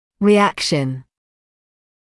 [rɪ’ækʃn][ри’экшн]реакция; ответное действие